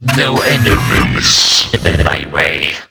Android Infantry (Sound Voice)
The compilation contains 17 sounds, and here are samples of the android's best dialogue.
android_move4_830.wav